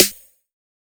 Waka SNARE ROLL PATTERN (89).wav